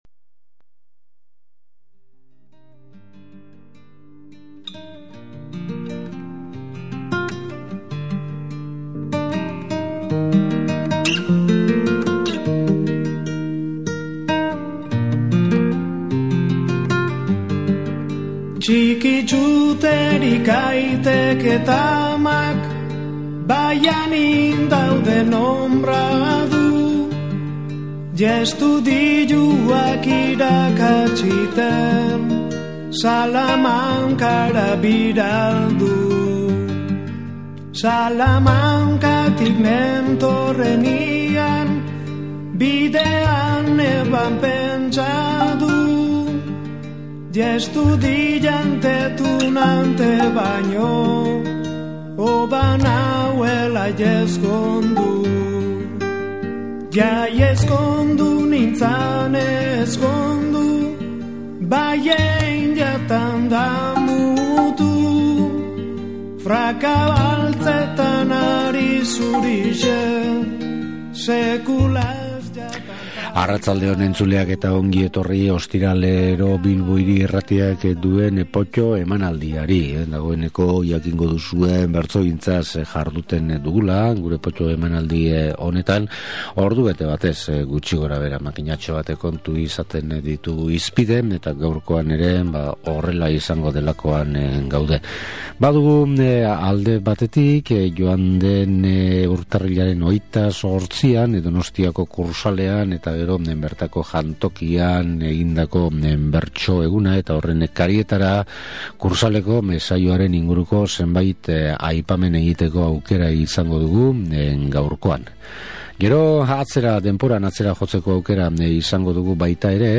Potto irratsaioak bertsolaritzaren bide zahar-berriak erakusten dizkugu. Aurtengo Bertso Egunaren harira jardun izan dugu eta, Kursaaleko giroa profitatuz, duela 40 urteko benetako saio bat tartekatu dugu, Donostian 1968. urtean jokatutako sariketa, alegia.